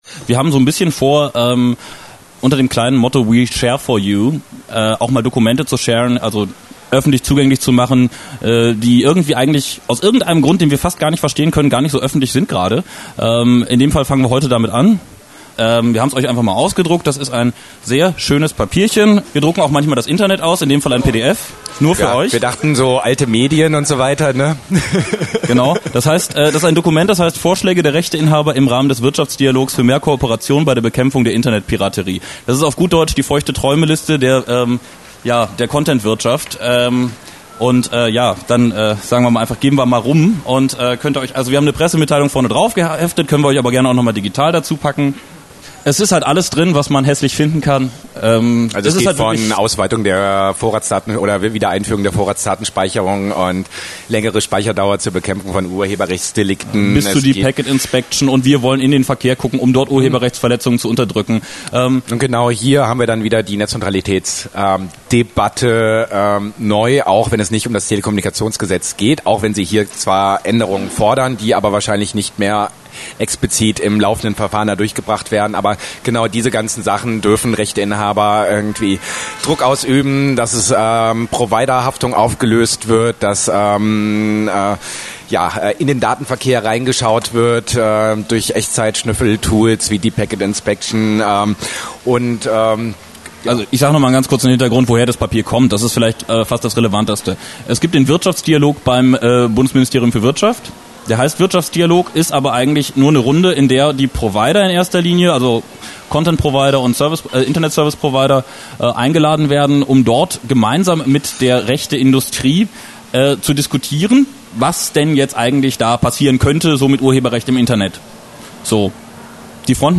Was: Hintergrundgespräch Digitale Gesellschaft e.V.
Wo: Berlin, Café Sankt Oberholz